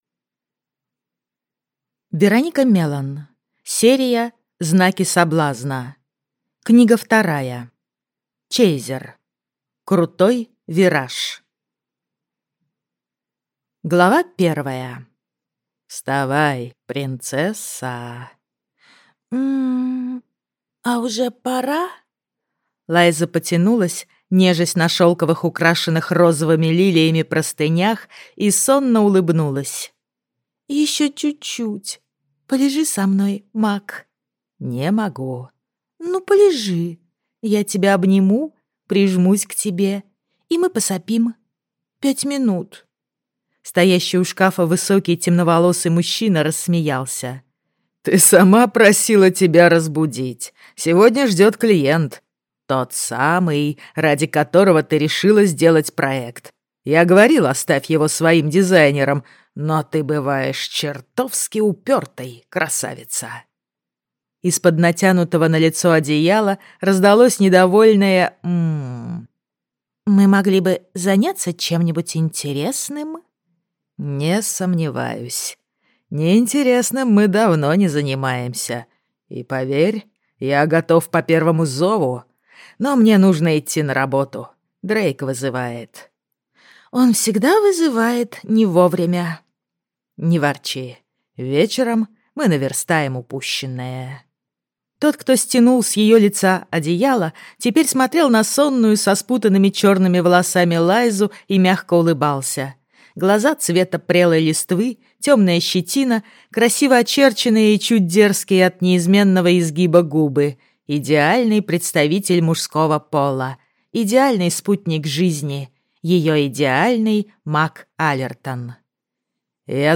Аудиокнига Чейзер. Крутой вираж - купить, скачать и слушать онлайн | КнигоПоиск